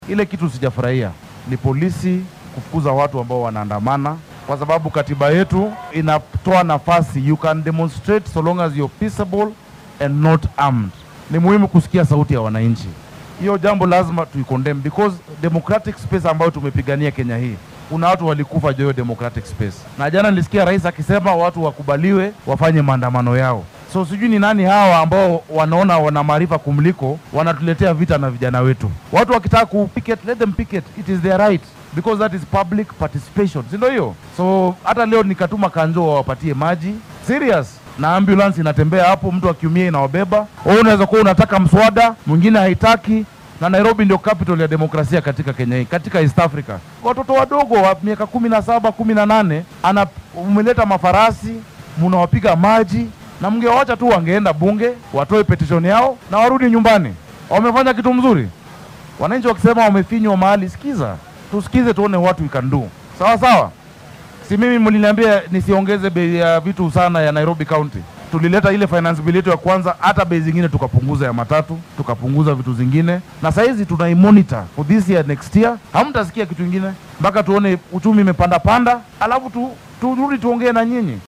Xilli uu dhagax dhigayay dhismaha fagaaraha Kihumbuini Stadium ee xaafadda Westlands ayuu Sakaja sheegay in qof kasta oo kenyaan ah uu xaq dimuqraadi ah u leeyahay inuu muujiyo dareenkiisa ku aaddan waxyaabaha uunan ku qancin ee ay dowladdu waddo.